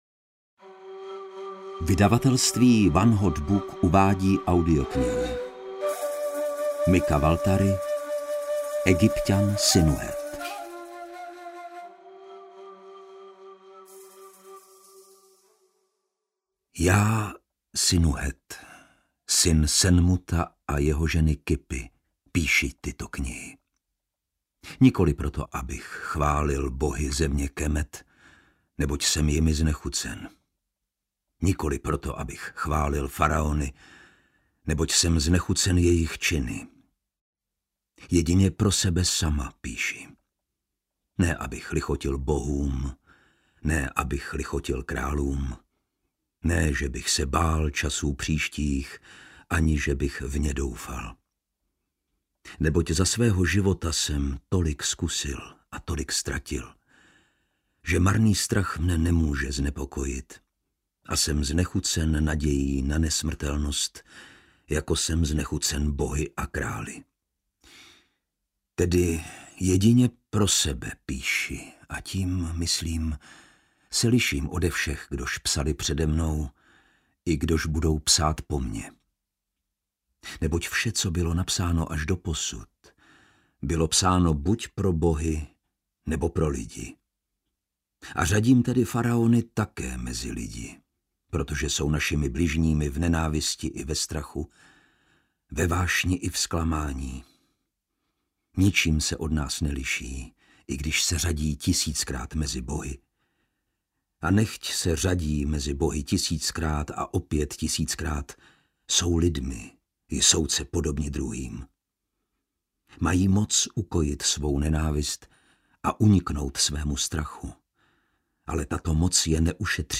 Egypťan Sinuhet audiokniha
Ukázka z knihy